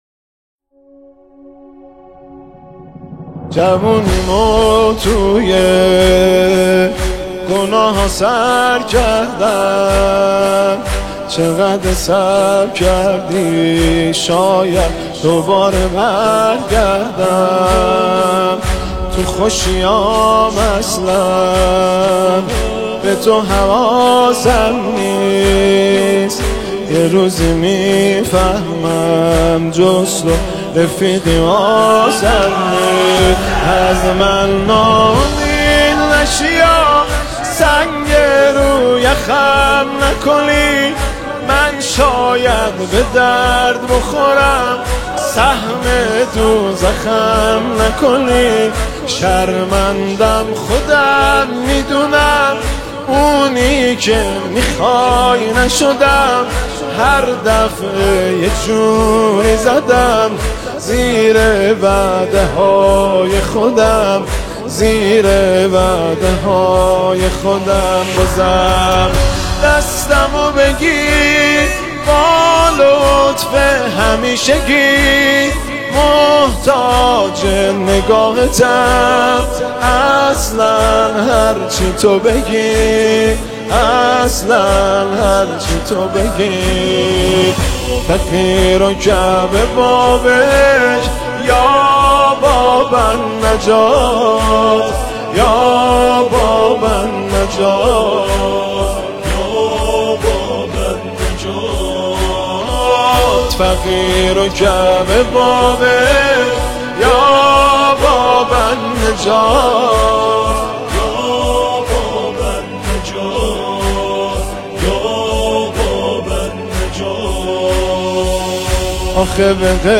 مداحی آذری نوحه ترکی نماهنگ